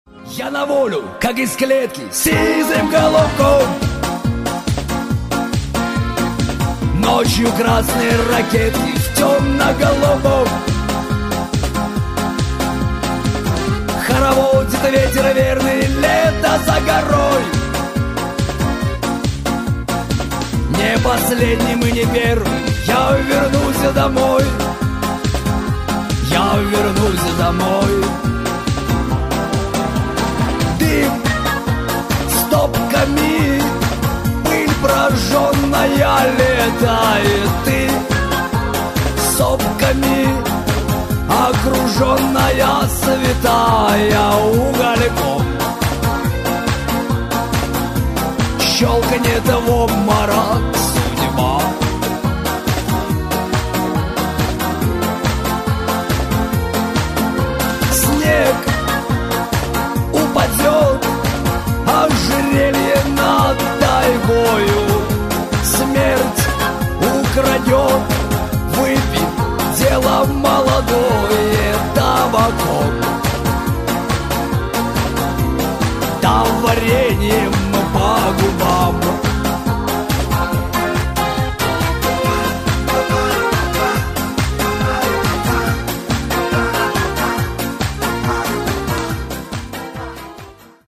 • Качество: 128, Stereo
мужской вокал
громкие
аккордеон
русский шансон